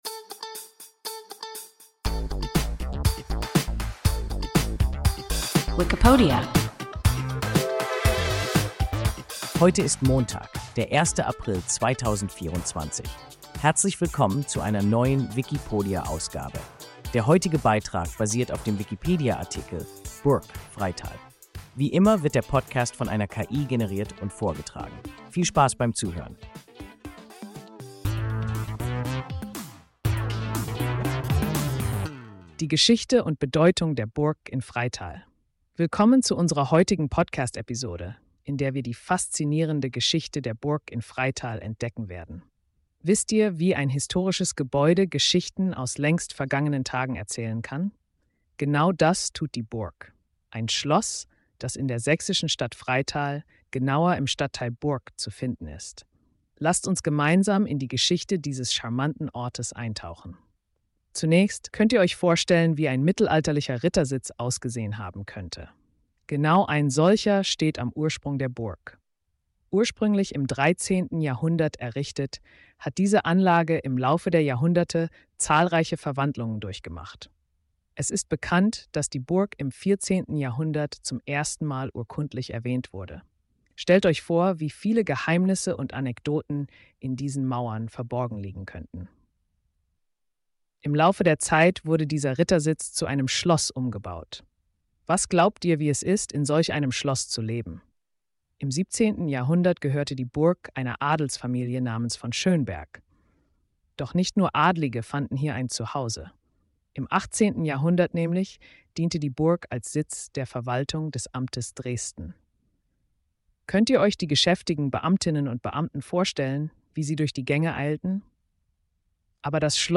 Burgk (Freital) – WIKIPODIA – ein KI Podcast